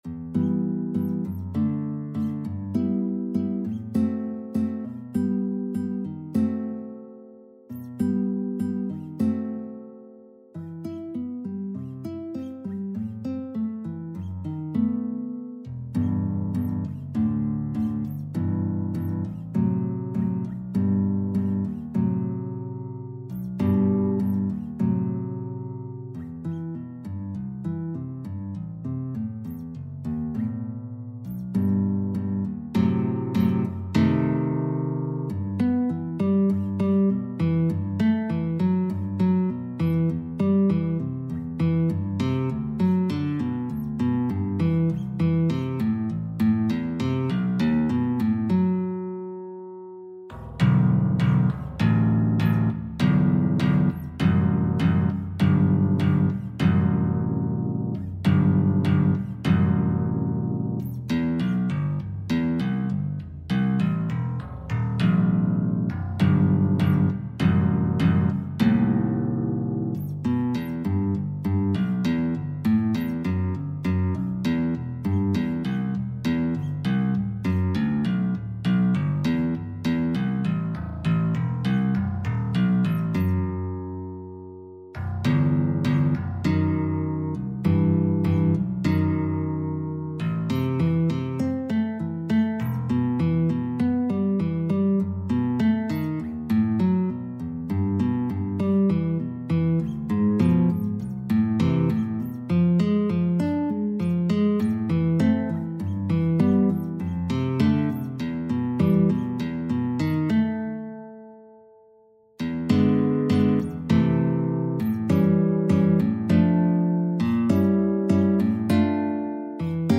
4/4 (View more 4/4 Music)
E4-E7
Instrument:
Classical (View more Classical Guitar Music)
Free Guitar Sheet music